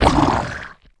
damage_1.wav